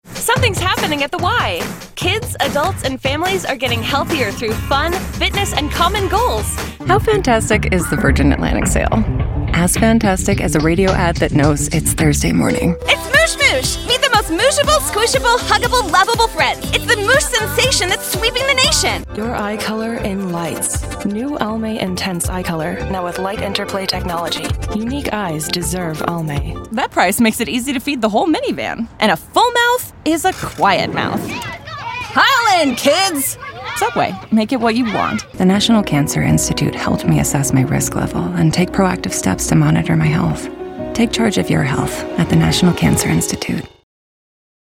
Voice demos
Versatile and flexible mezzo vocalist.